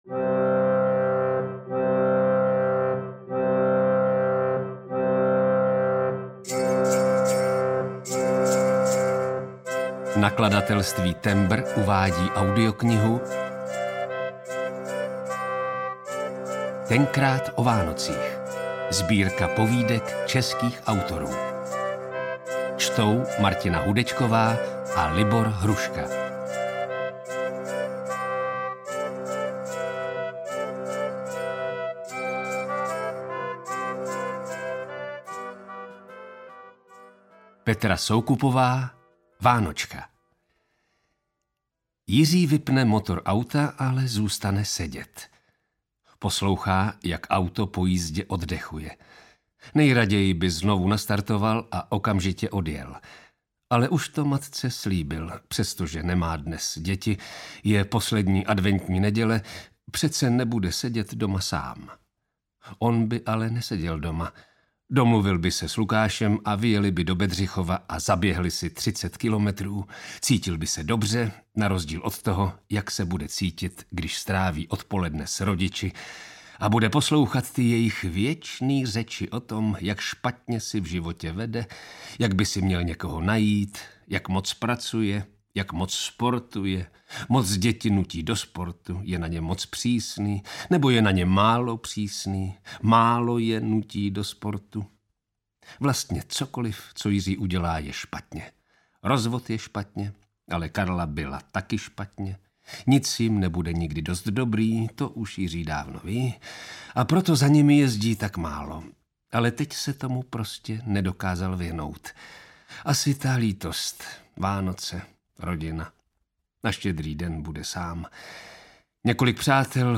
Tenkrát o Vánocích audiokniha
Ukázka z knihy
tenkrat-o-vanocich-audiokniha